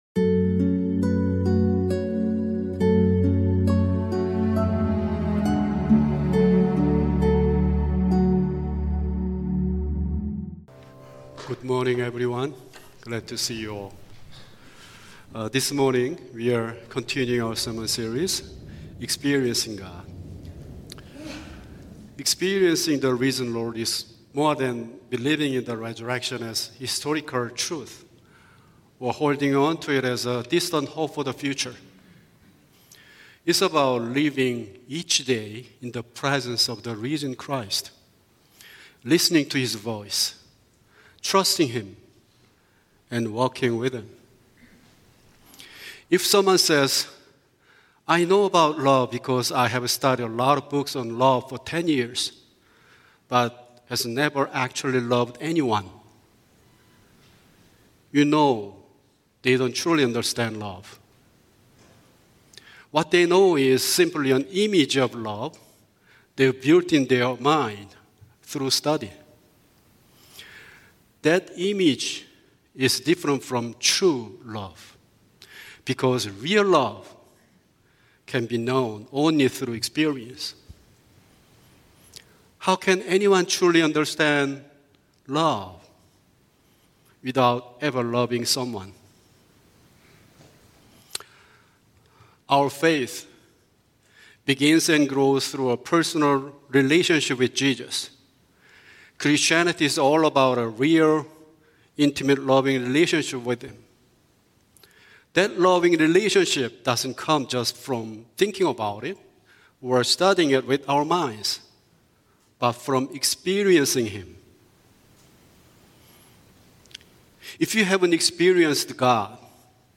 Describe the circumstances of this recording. Sermons from River Park Church (CRC) in Calgary, Alberta (AB).